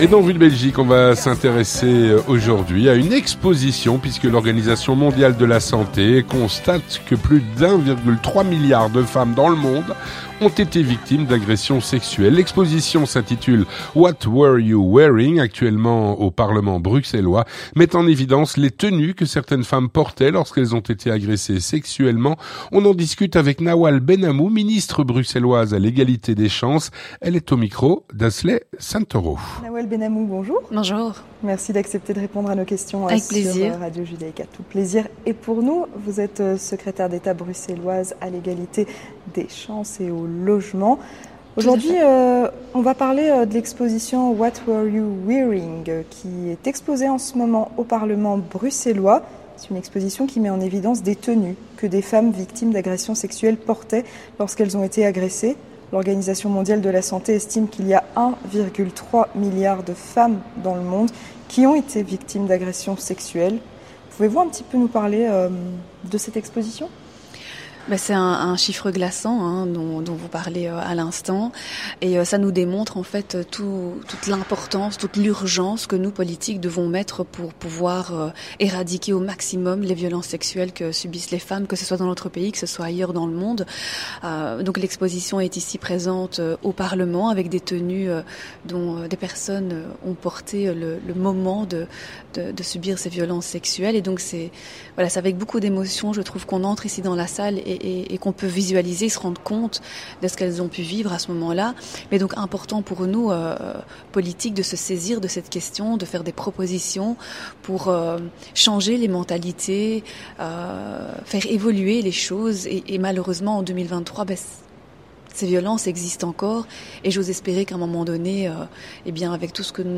Avec Nawal Ben Hamou, secrétaire d'Etat à l'Egalité des chances et au Logement